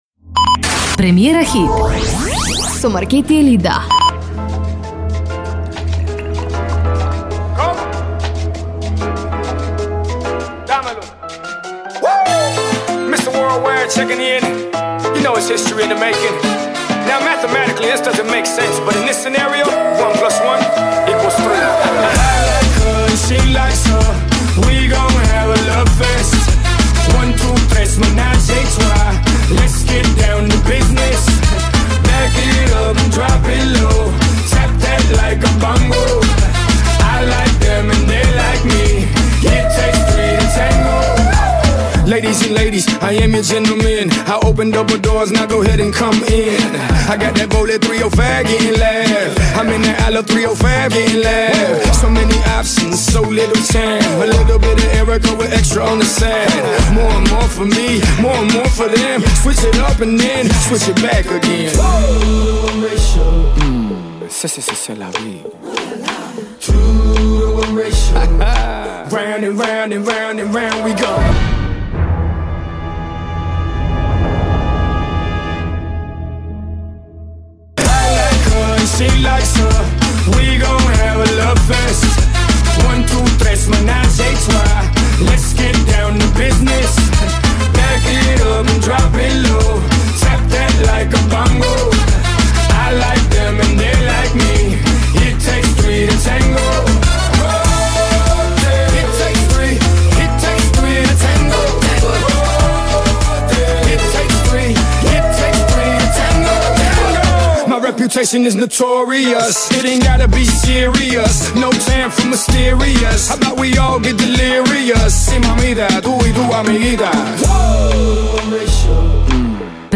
Песната е во латино ритам